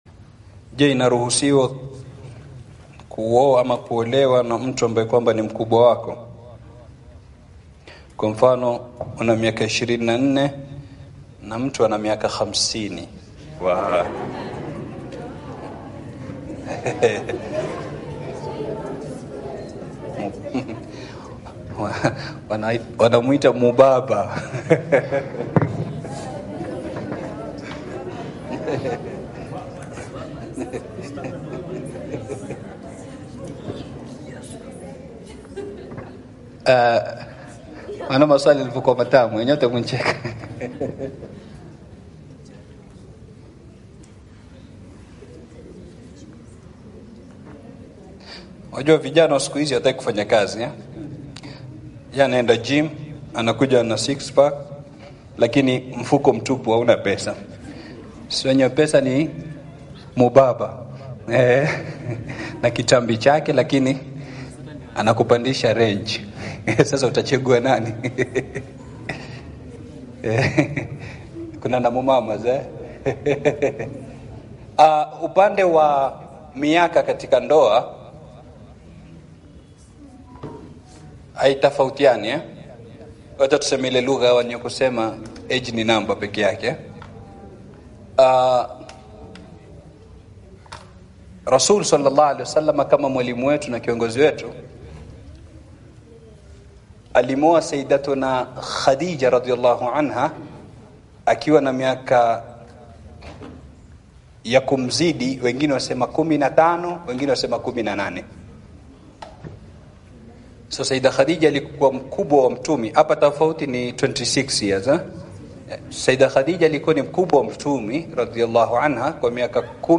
Maswali Na Majibu Kwa Vijana Wakike Na Wakiume Katika Seminar Ya Masjid Al Huda.